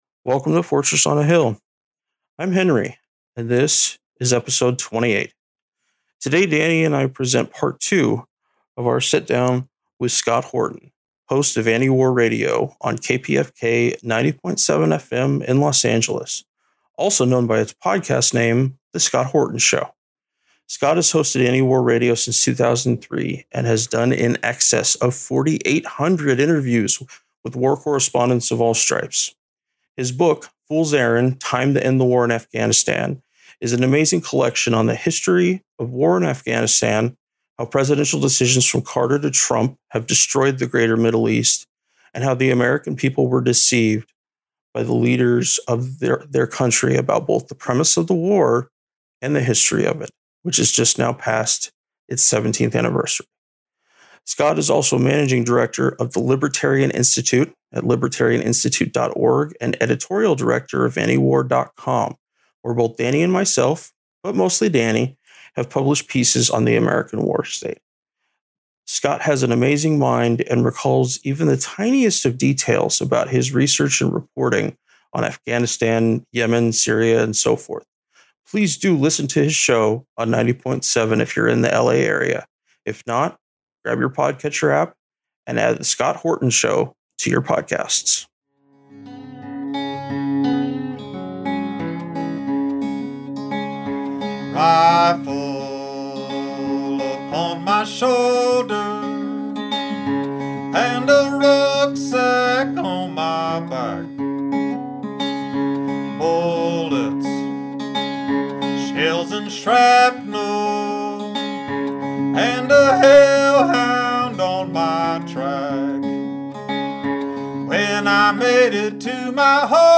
interview Part 2 – Ep 28